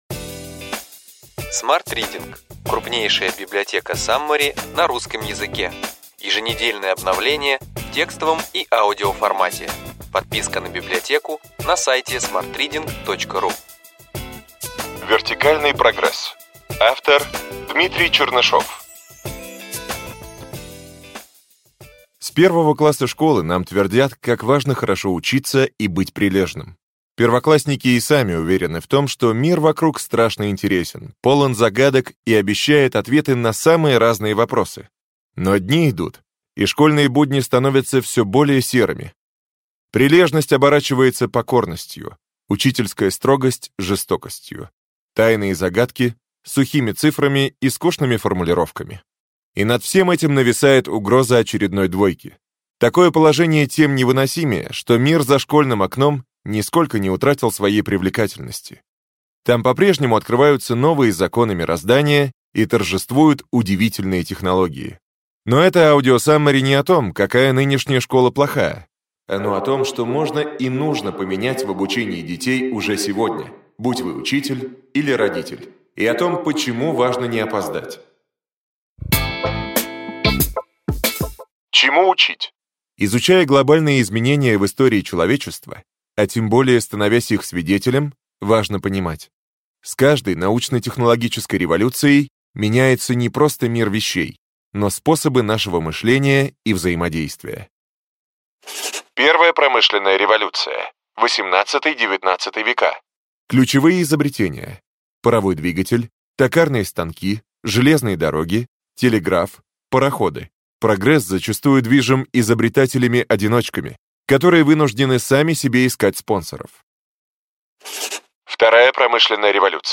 Аудиокнига Ключевые идеи книги: Вертикальный прогресс.